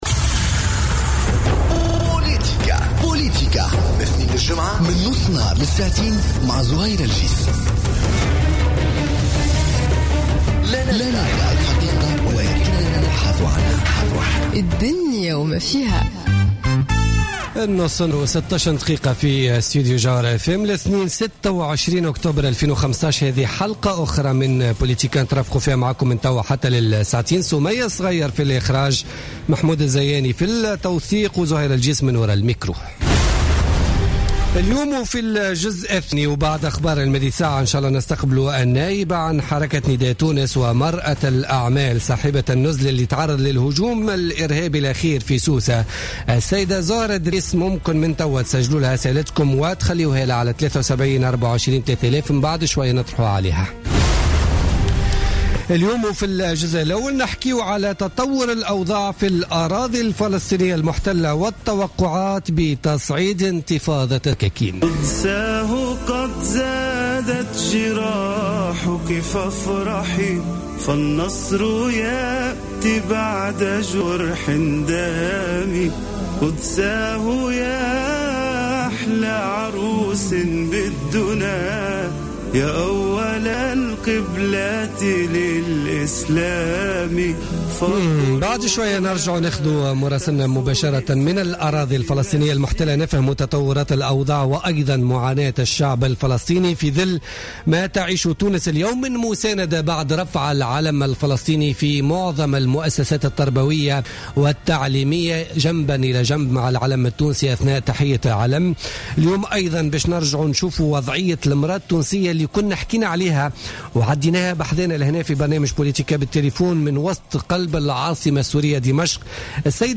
Le point sur la situation en Palestine / Interview avec Zohra Driss